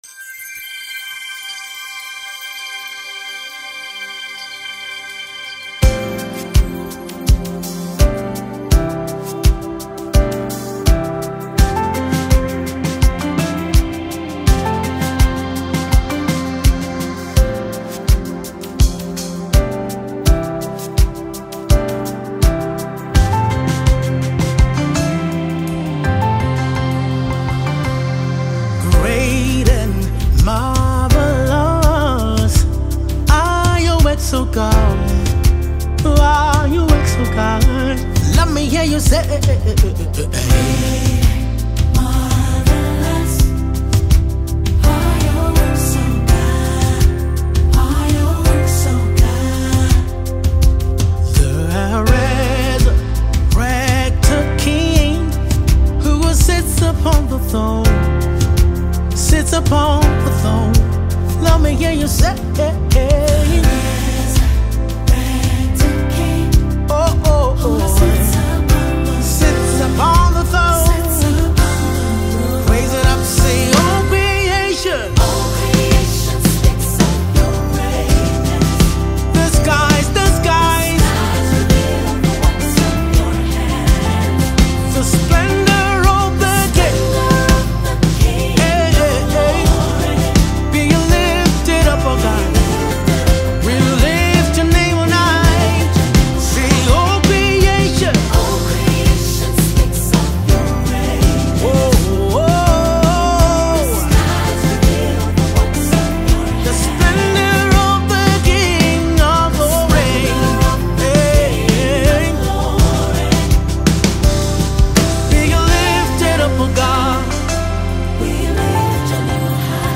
Mp3 Gospel Songs
South African gospel music artist and preacher